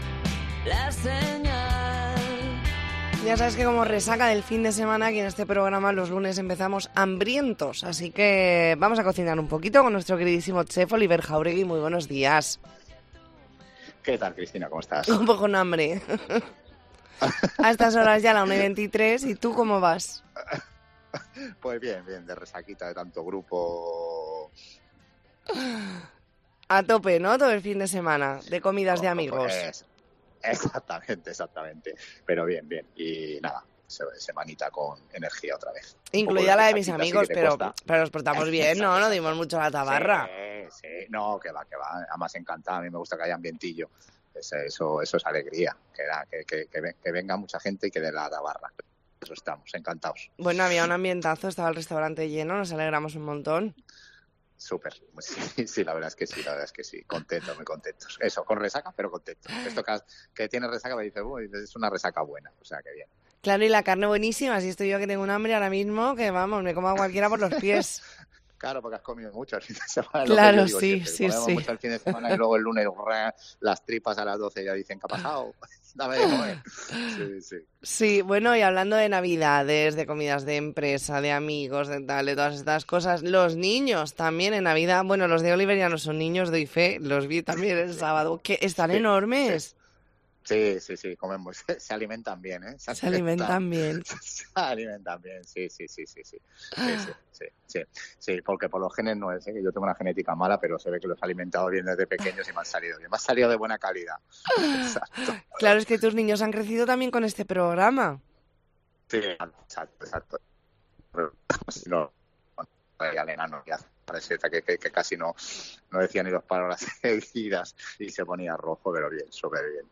Entrevista en La Mañana en COPE Más Mallorca, lunes 18 de diciembre de 2023.